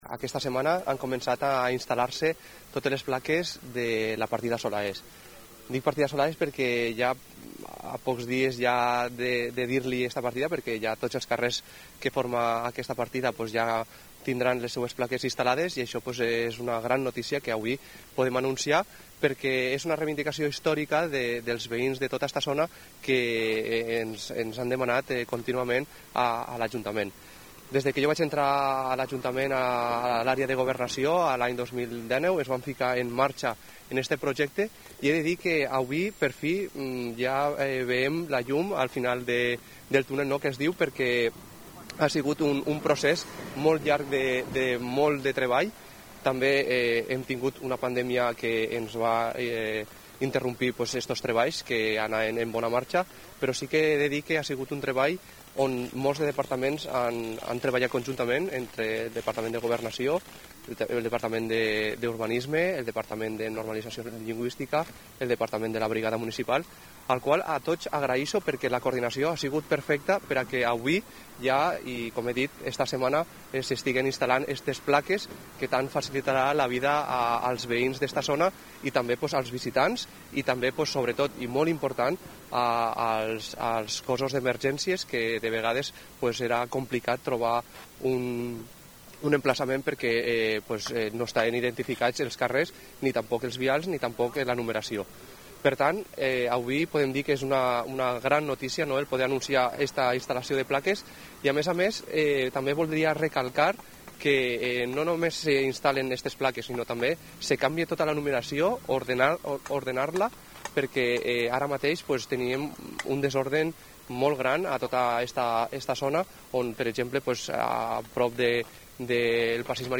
Ilde Añó, regidor de Governació